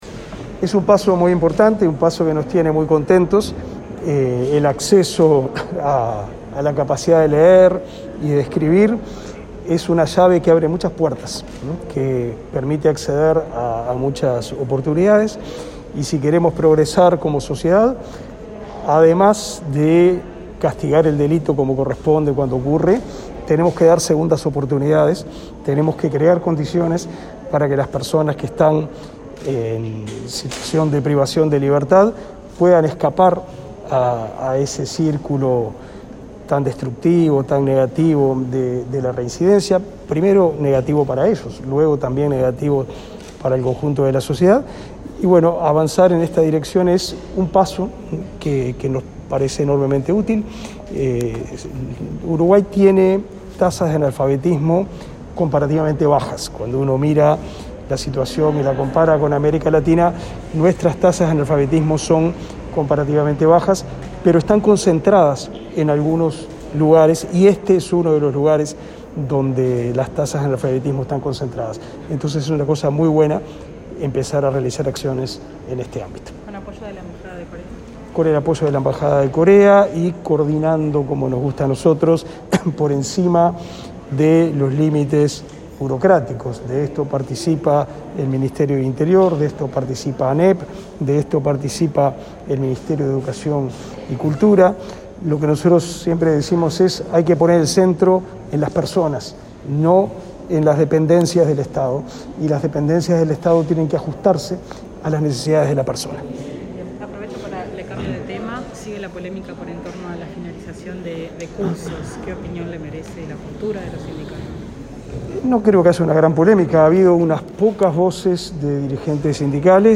Declaraciones a la prensa del ministro de Educación y Cultura, Pablo da Silveira